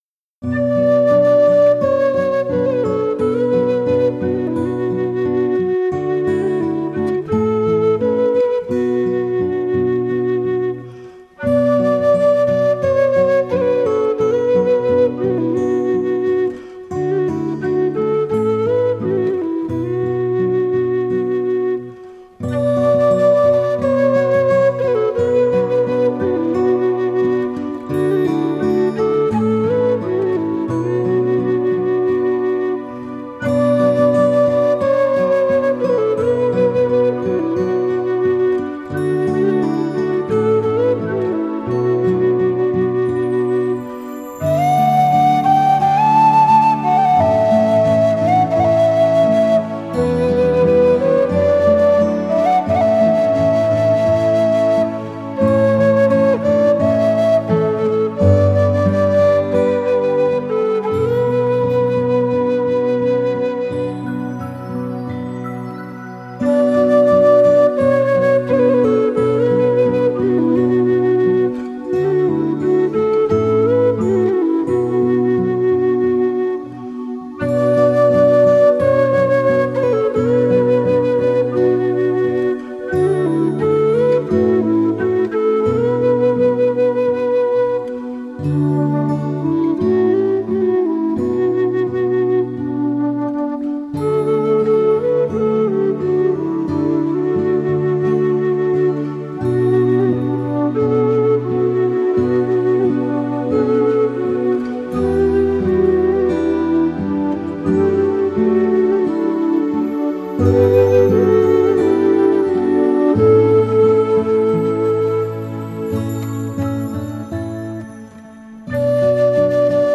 他深情合奏，旋律动人浪漫，悦耳而富柔情风格；木吉他银色铿锵清彻而具感染力
优美的旋律，柔缓的节奏所营造的大自然般的宁静气氛确实给人带来